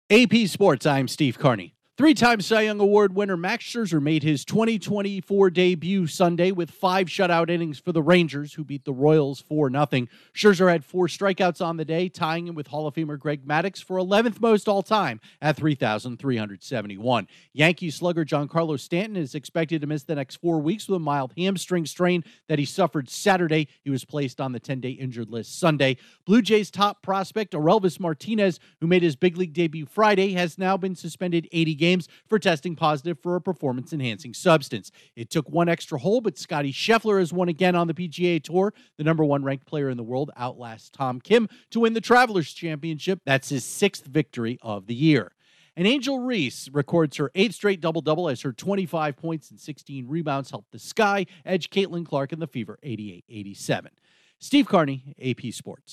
The latest in sports